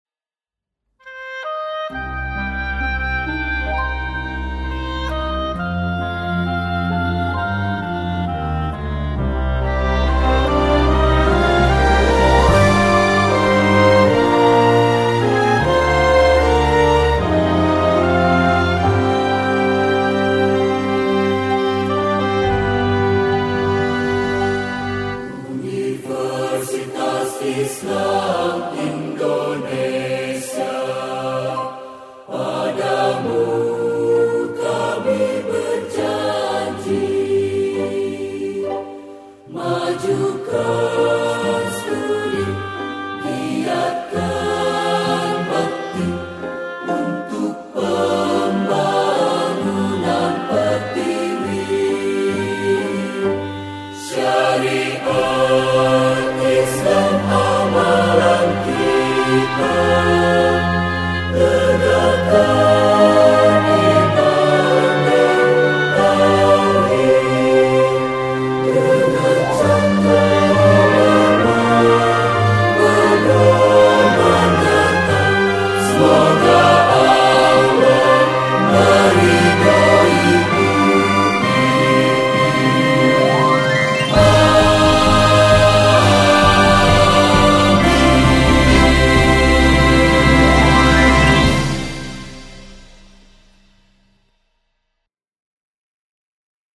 Himne-Universitas-Islam-Indonesia-Orchestra-Choirs.mp3